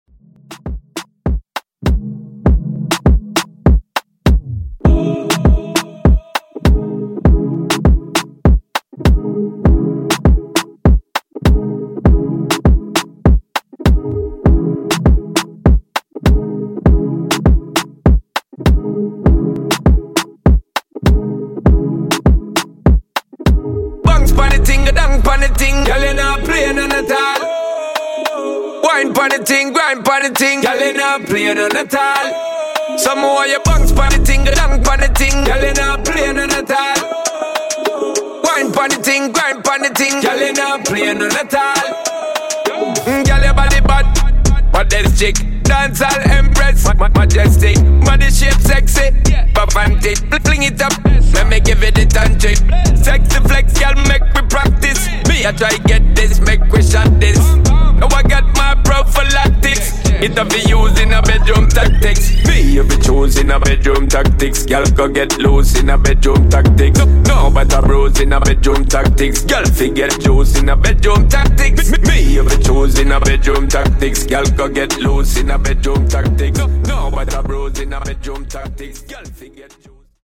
In-Outro Afro)Date Added